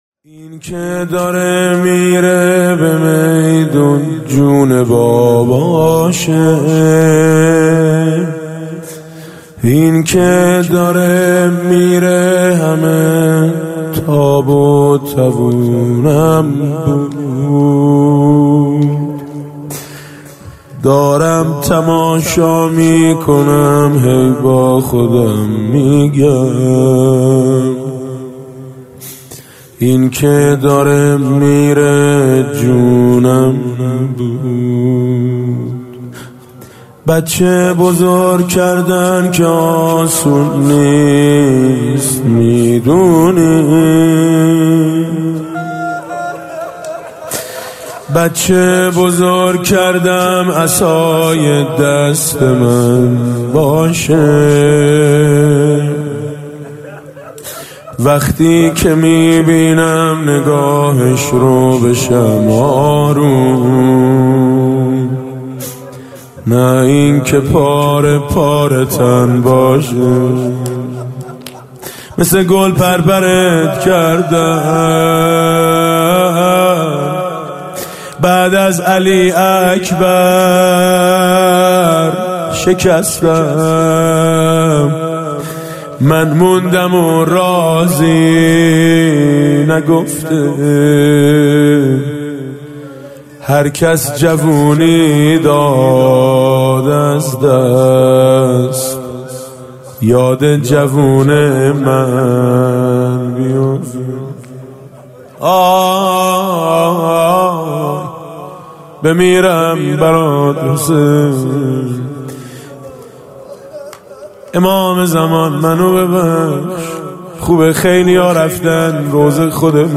حاج میثم مطیعی